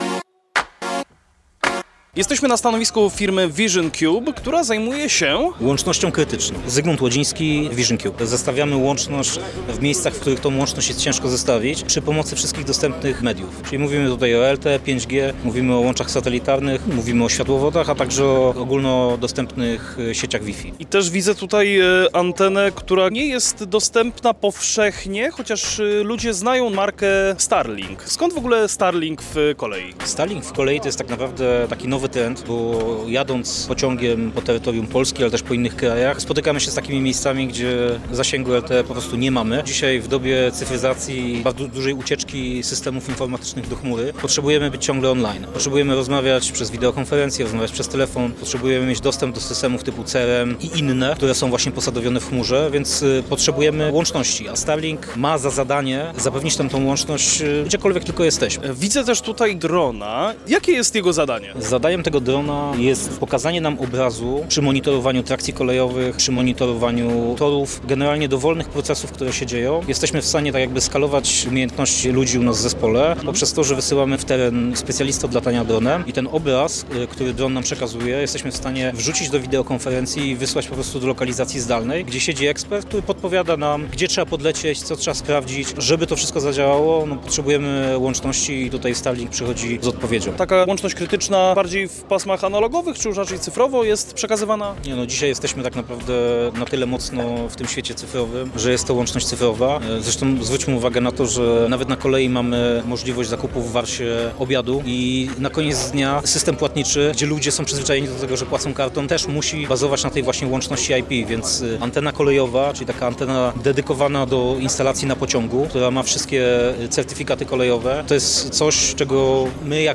Zapraszamy Was do wysłuchania pierwszego wywiadu z serii materiałów nagranych podczas 16. Międzynarodowych Targów Kolejowych TRAKO 2025!